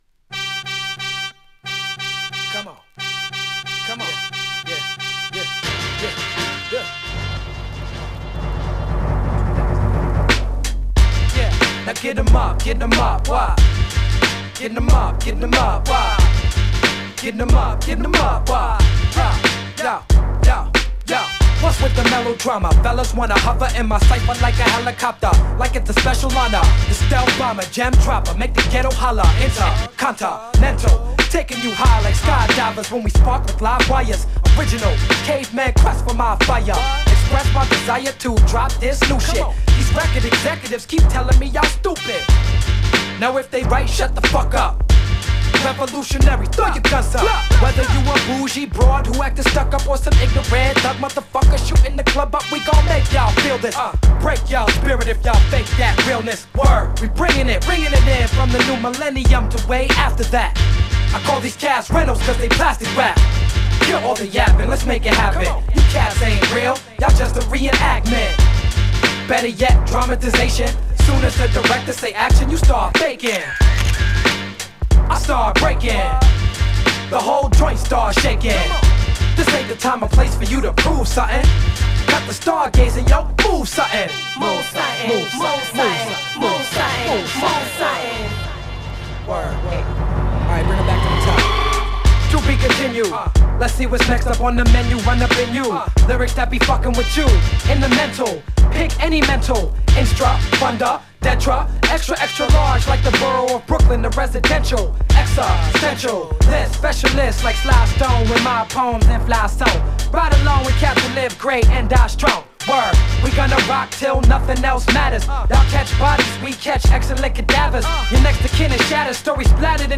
> HIPHOP
インパクトの強いハーコーなトラックが印象深い